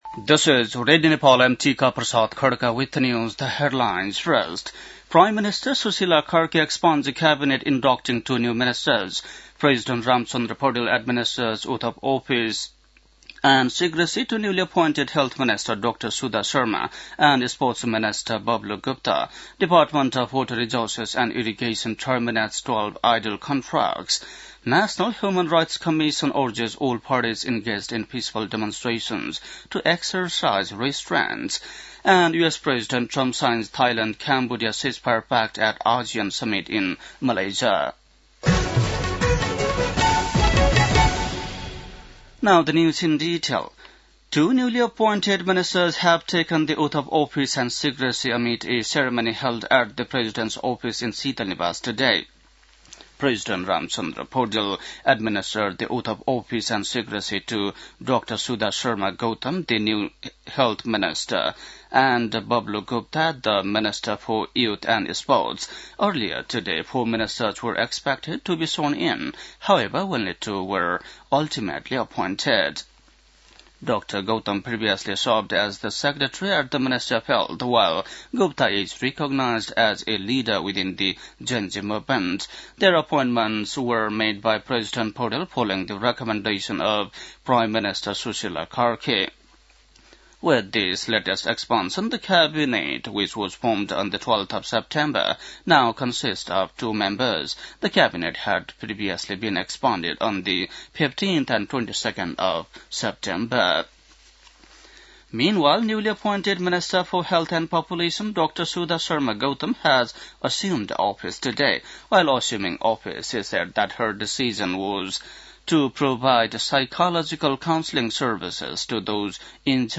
बेलुकी ८ बजेको अङ्ग्रेजी समाचार : ९ कार्तिक , २०८२
8-pm-english-news-1-1.mp3